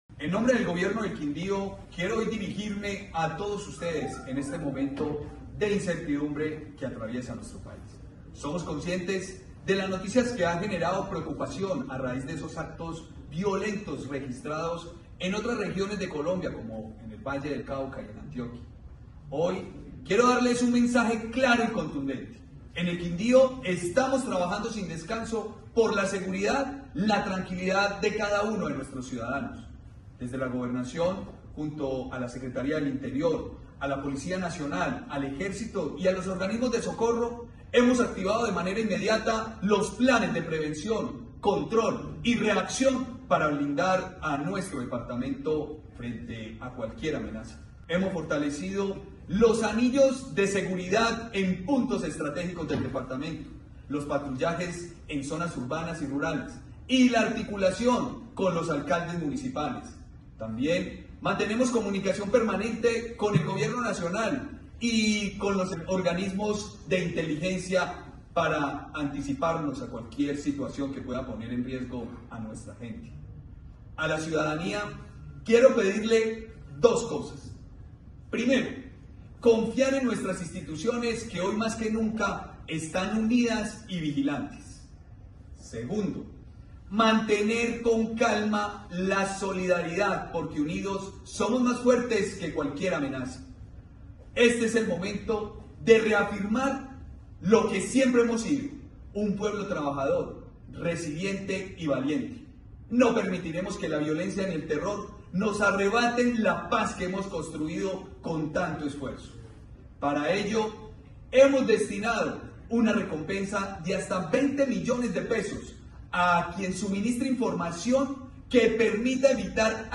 Gobernador del Quindío, Juan Miguel Galvis